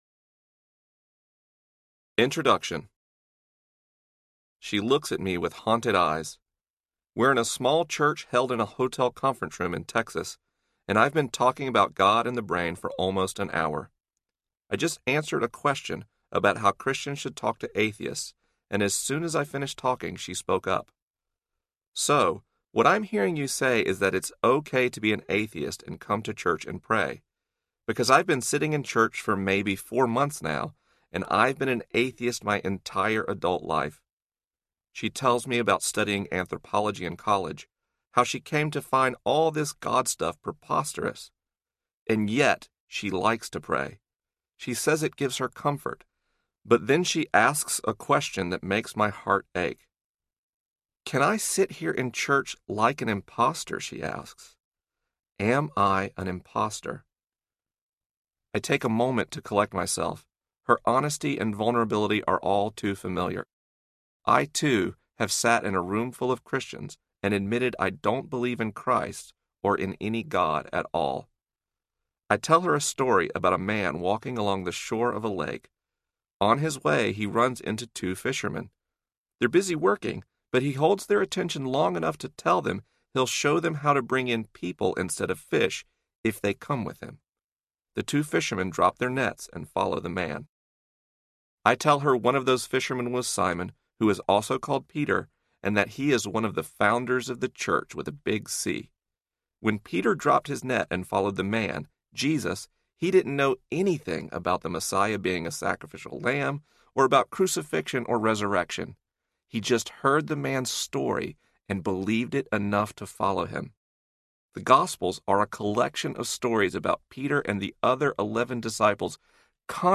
Finding God in the Waves Audiobook
Narrator